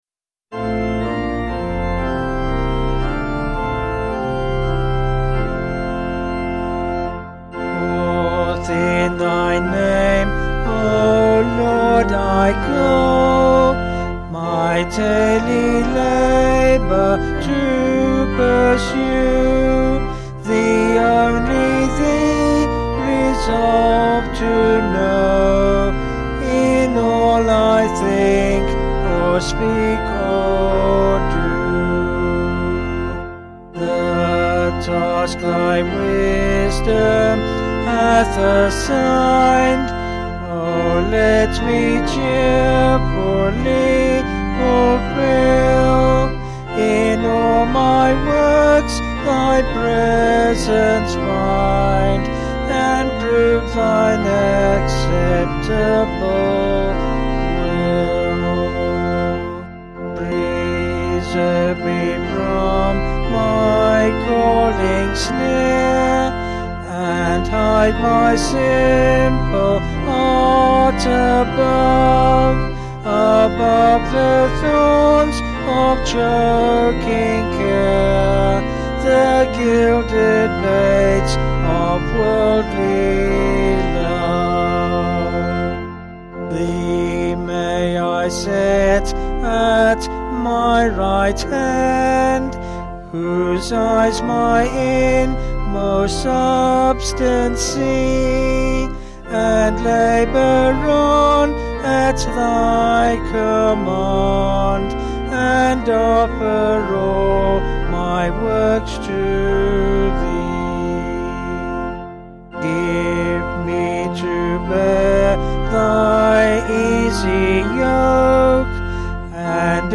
Vocals and Organ   154.7kb Sung Lyrics